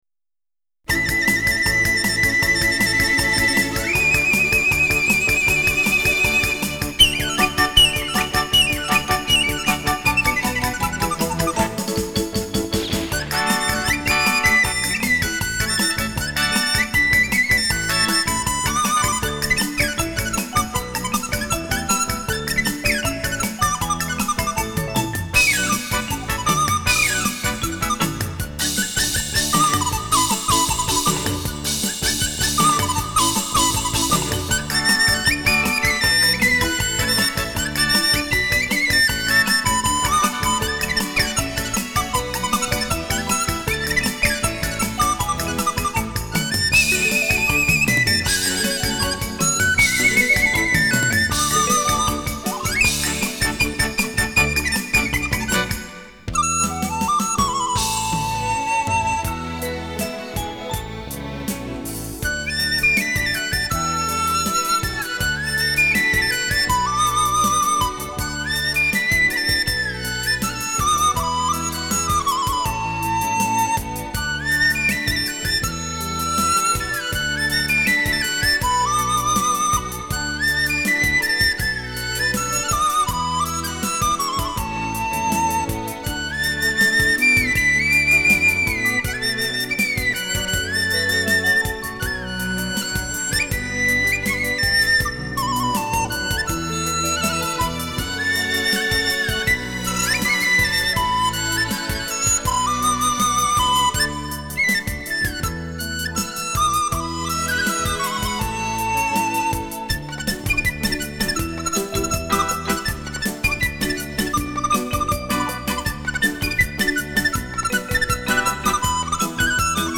笛子、葫芦丝、洞萧、巴乌独奏